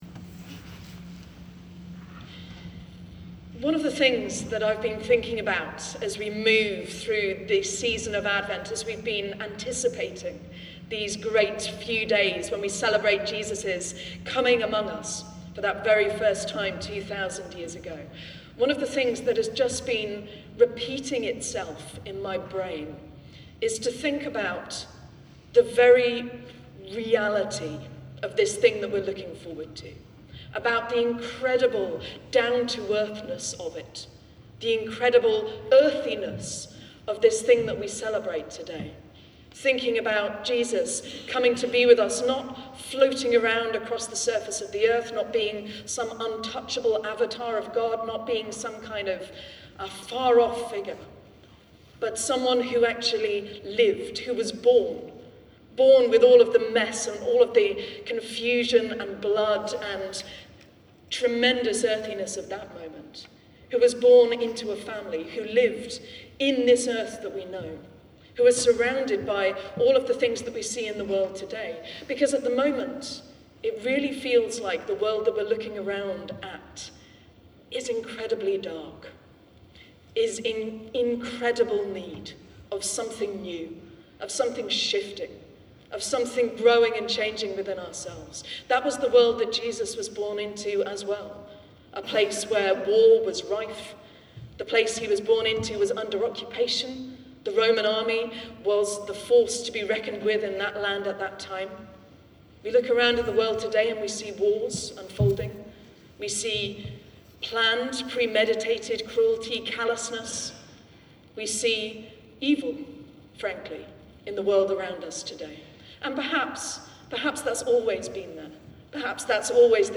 Sermon for Midnight Mass 24th December 2025